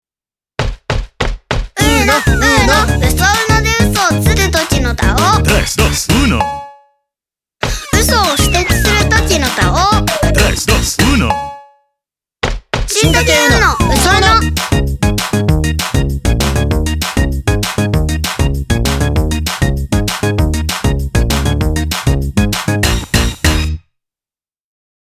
TVCM
SONG ELECTRO